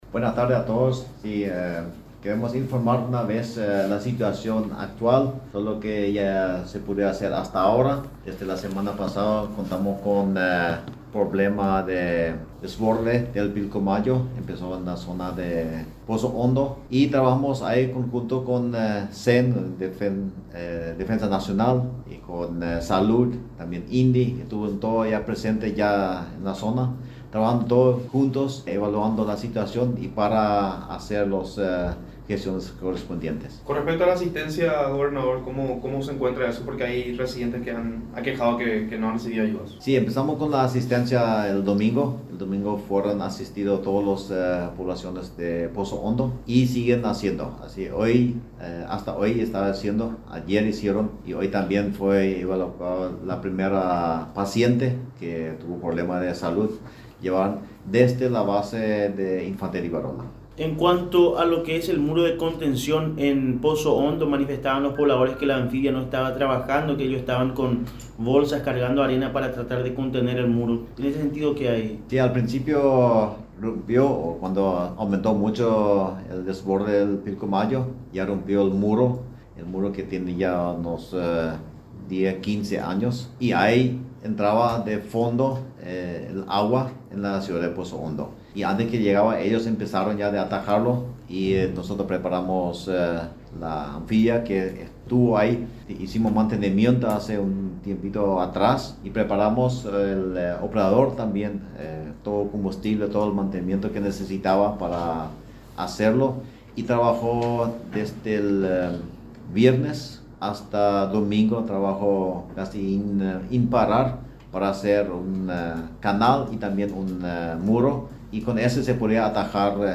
Entrevistas / Matinal 610 Situación en la zona del Pilcomayo Mar 19 2025 | 00:20:30 1x 00:00 / 00:20:30 Subscribe Share RSS Feed Share Link Embed